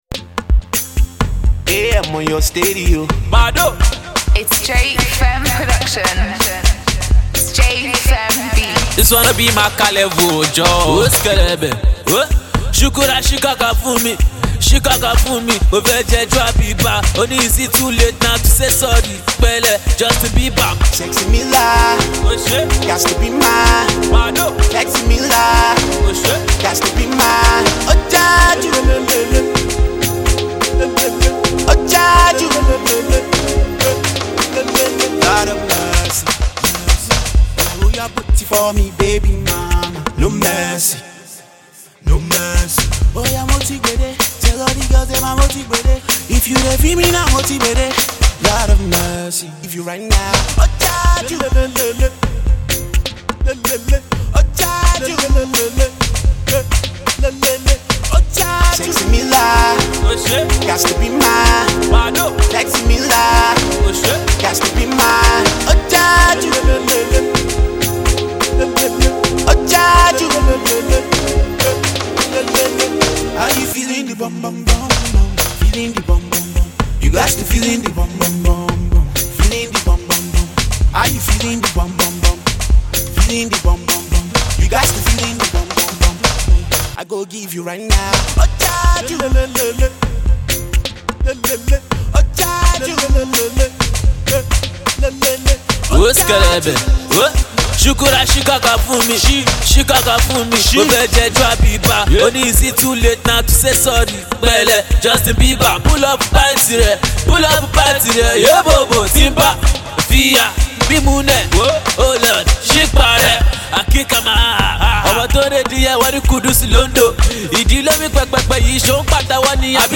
popular rap star